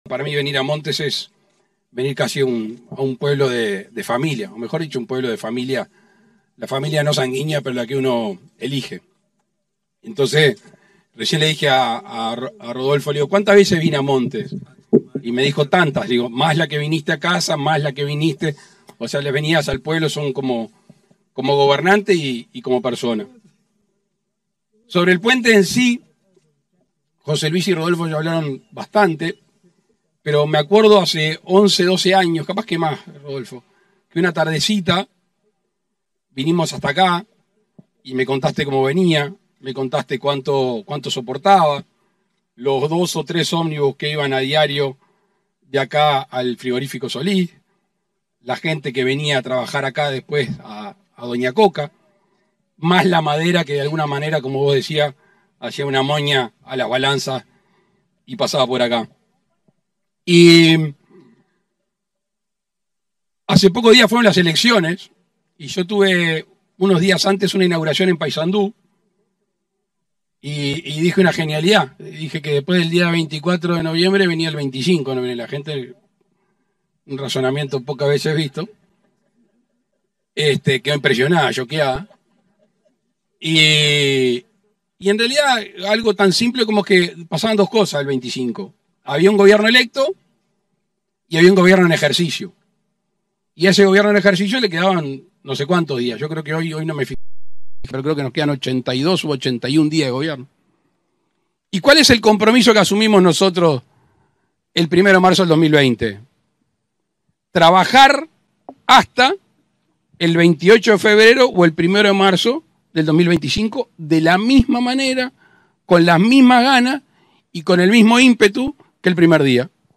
Palabras del presidente de la República, Luis Lacalle Pou
Palabras del presidente de la República, Luis Lacalle Pou 11/12/2024 Compartir Facebook X Copiar enlace WhatsApp LinkedIn En el marco de la inauguración de un puente sobre el arroyo Solís Grande, en la localidad de Montes, se expresó el presidente de la República, Luis Lacalle Pou.